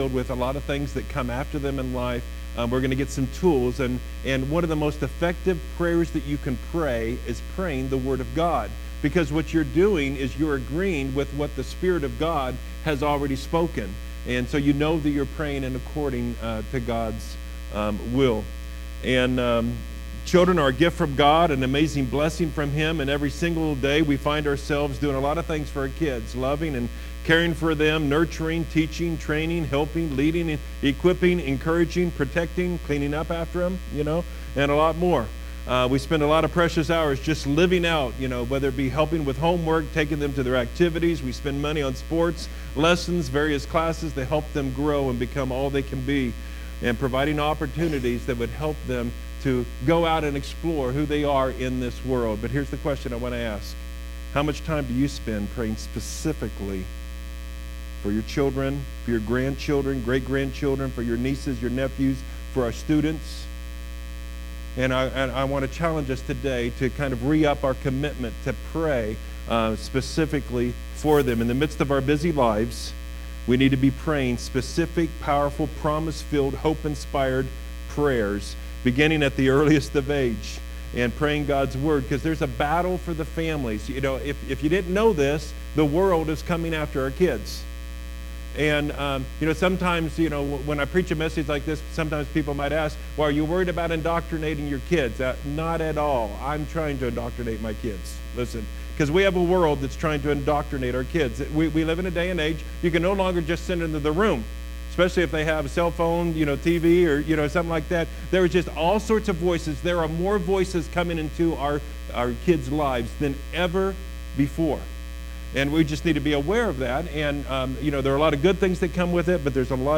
2018 Sermons